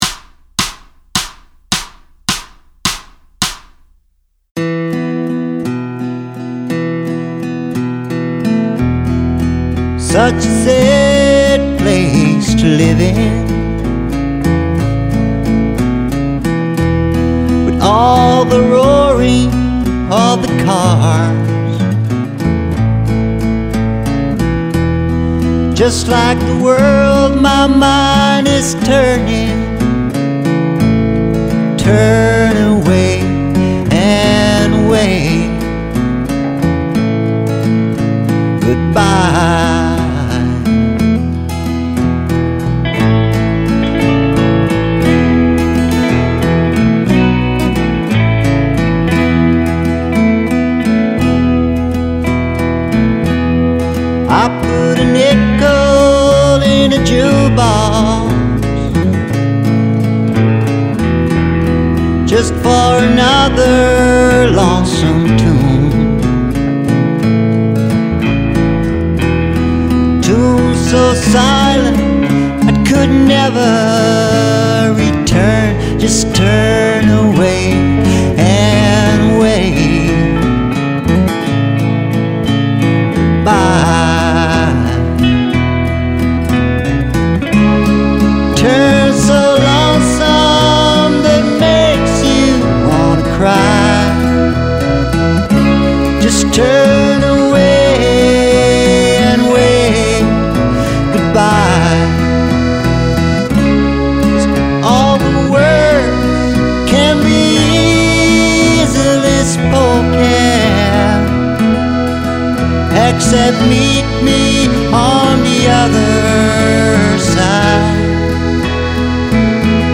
Guitars, Keyboards, Synth Bass, Accordion
Bass
Back Vocal
Drums
Harp
Recorded, Mix, Master in Medulin